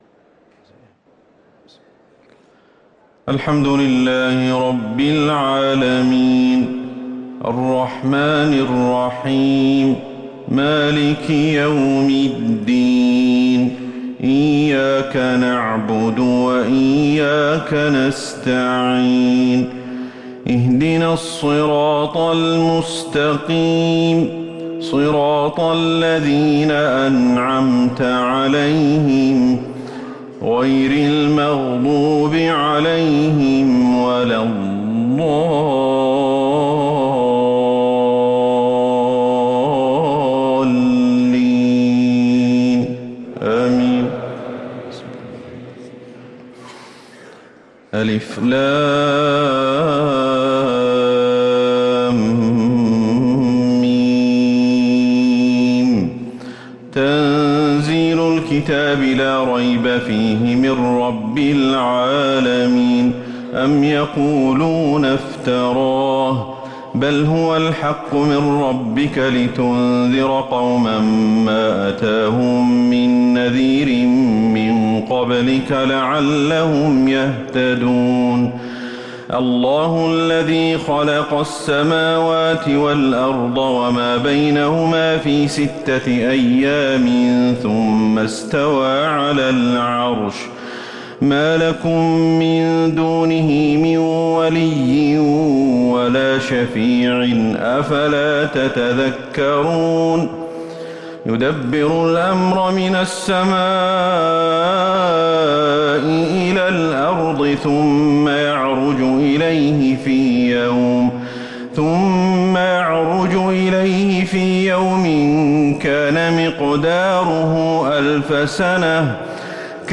صلاة الفجر للقارئ أحمد الحذيفي 19 ذو الحجة 1444 هـ
تِلَاوَات الْحَرَمَيْن .